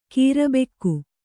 ♪ kīra bekku